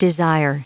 Prononciation du mot desire en anglais (fichier audio)
Prononciation du mot : desire